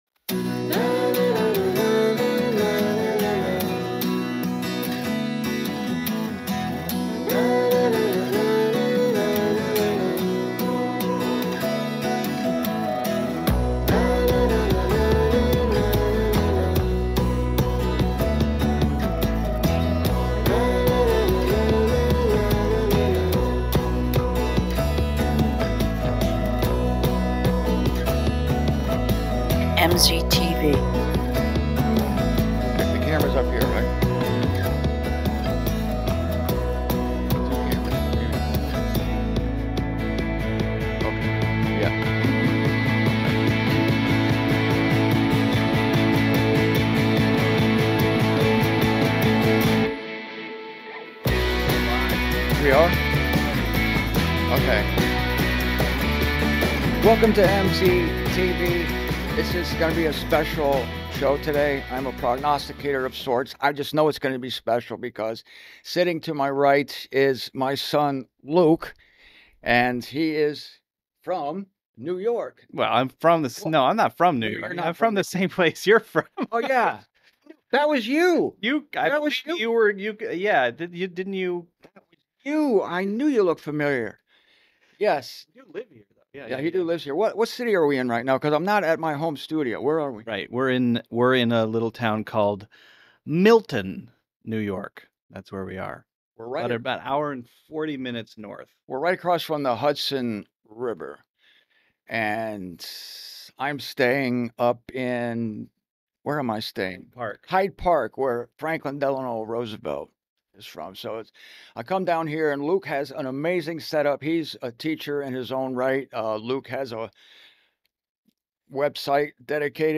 This generous young man offered me the use of his studio so that I could avoid recording another video in my car.